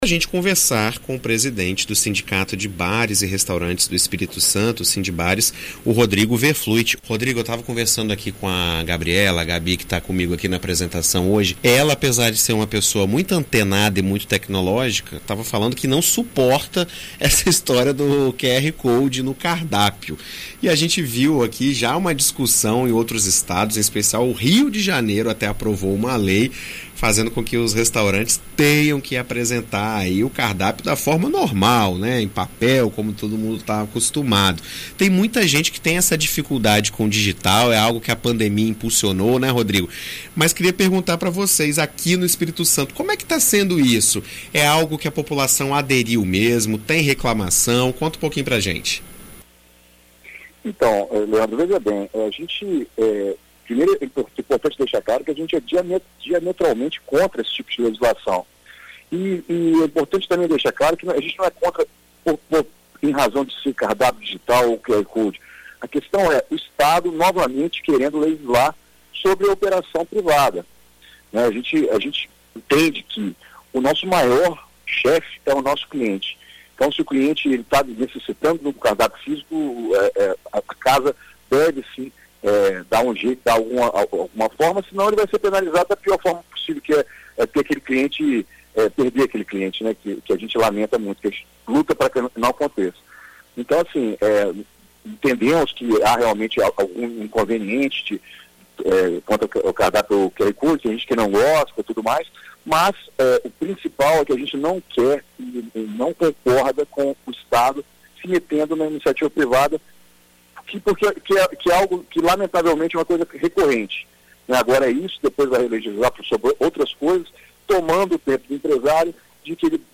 Em entrevista concedida à BandNews FM Espírito Santo